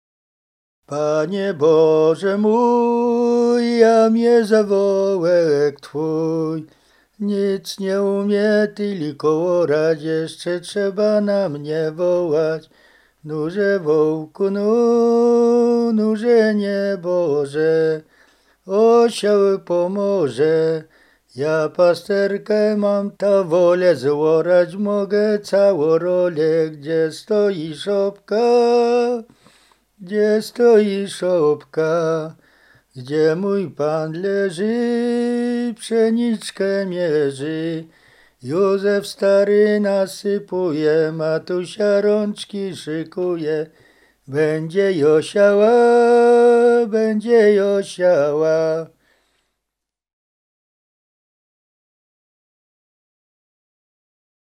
Roztocze
Kolęda
kolędowanie kolędy pastorałki